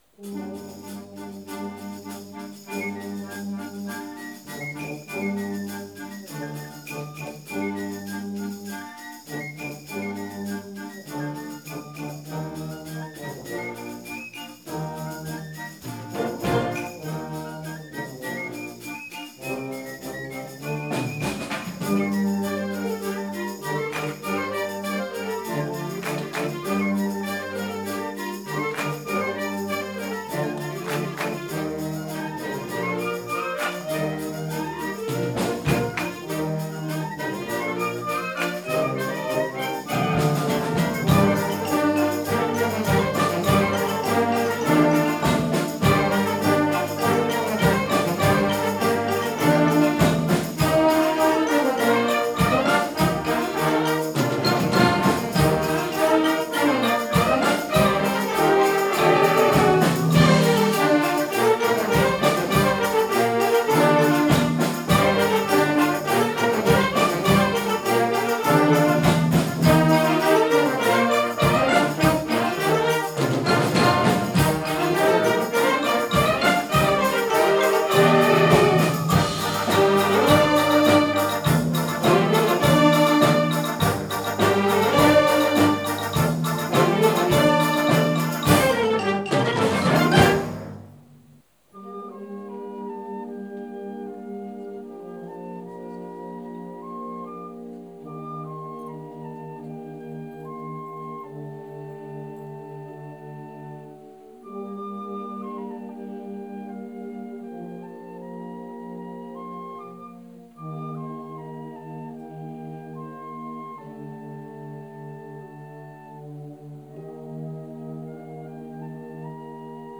Harmonie
Plus de 60 musiciens